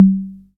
082 - CongaSyn.wav